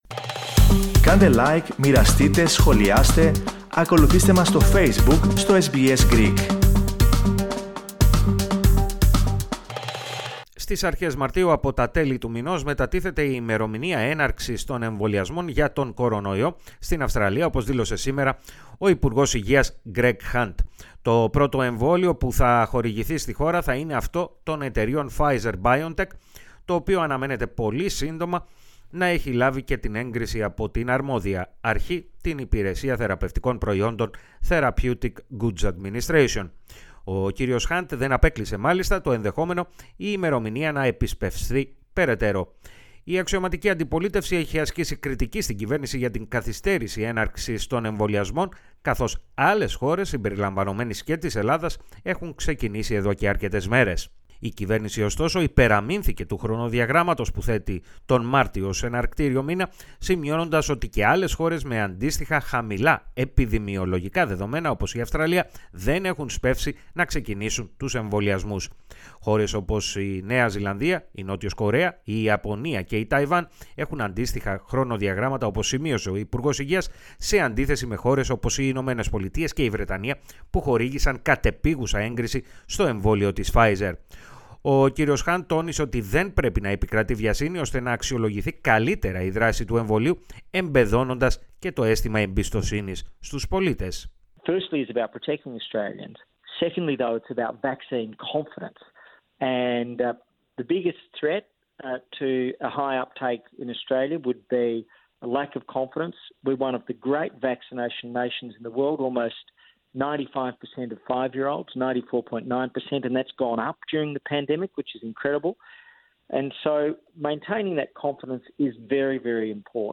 Κατά δύο εβδομάδες, επιταχύνεται το Αυστραλιανό χρονοδιάγραμμα έναρξης των εμβολιασμών για τον κορωνοϊό, την ίδια στιγμή που στην Ελλάδα, από τις 20 Ιανουαρίου ξεκινούν οι εμβολιασμοί για το γενικό πληθυσμό. Περισσότερα ακούστε στην αναφορά